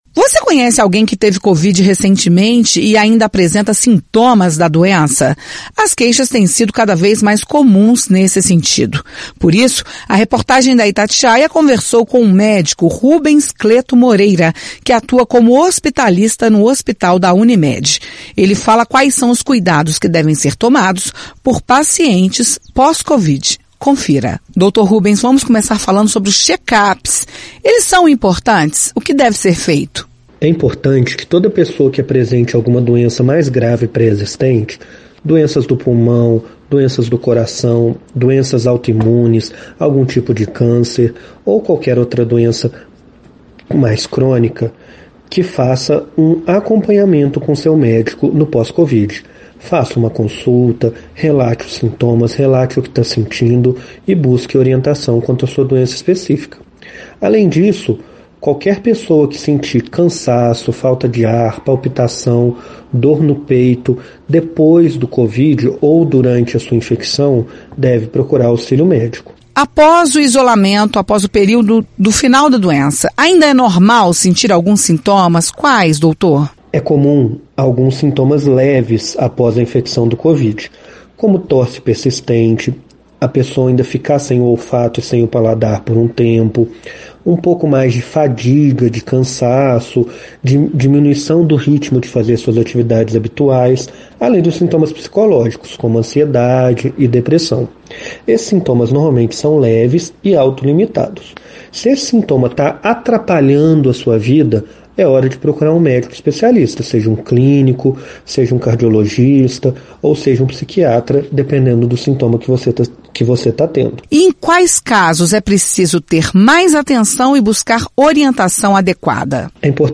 Pós-Covid19: Médico fala sobre cuidados e atenção com a saúde.